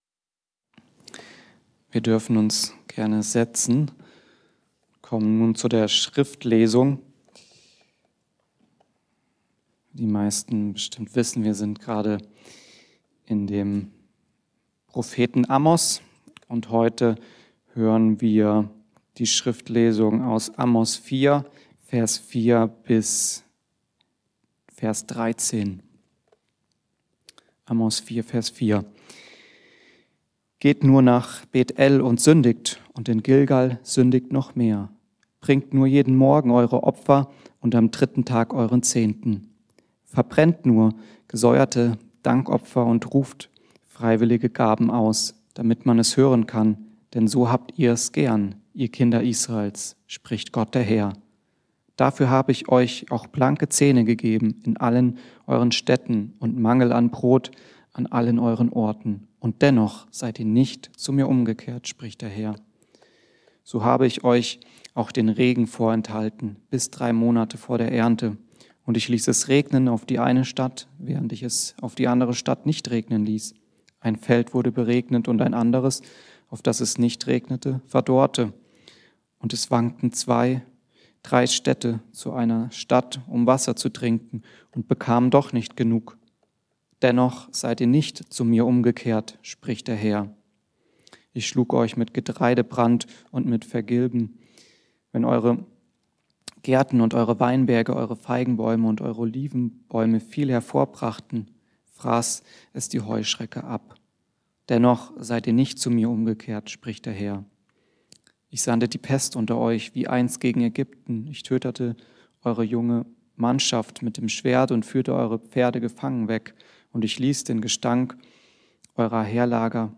Gott Oder Selbstbestimmung ~ Mittwochsgottesdienst Podcast